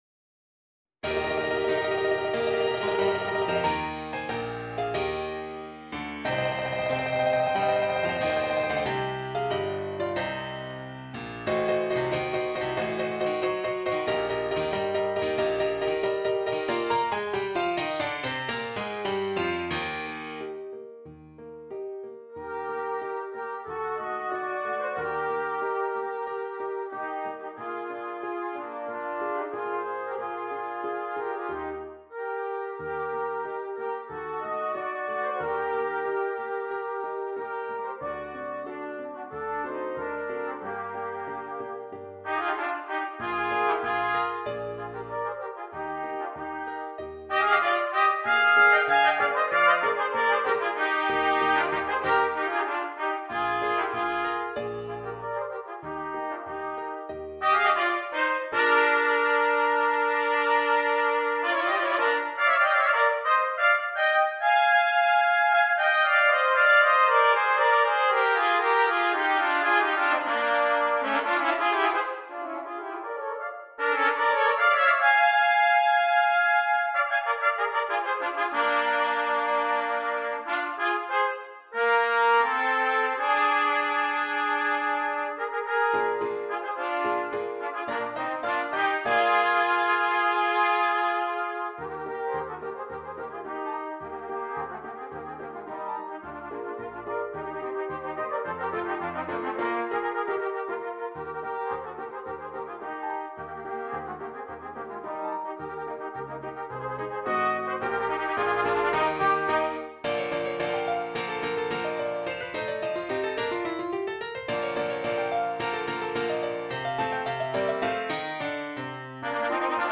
2 Cornets and Piano